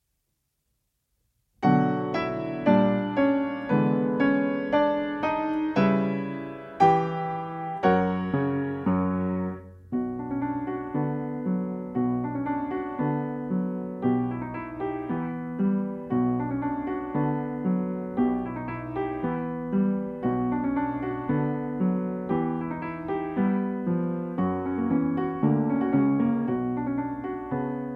I część: 116 bmp
Nagranie dokonane na pianinie Yamaha P2, strój 440Hz
piano